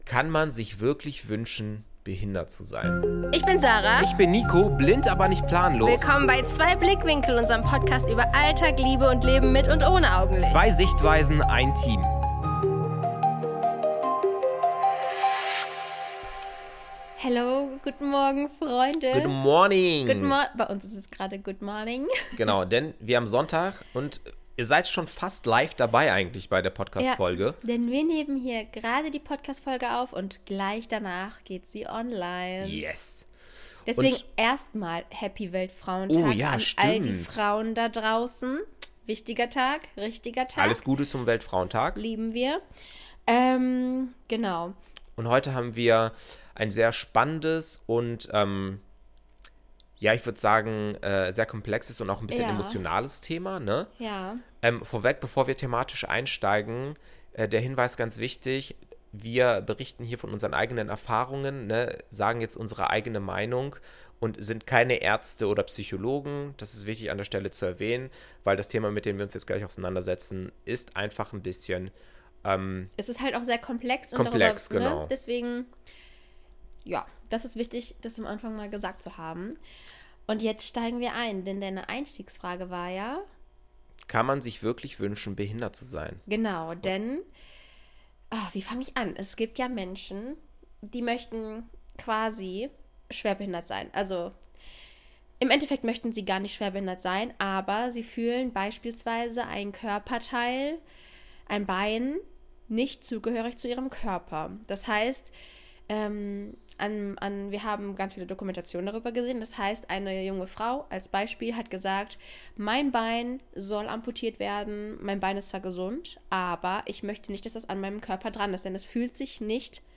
Was passiert, wenn ein blinder Mann und seine sehende Frau über Alltag, Liebe und Barrieren quatschen? Jede Menge ehrliche Einblicke, Lachanfälle und die Erkenntnis: Man kann dieselbe Welt mit ganz unterschiedlichen Augen sehen, oder eben auch mal gar nicht.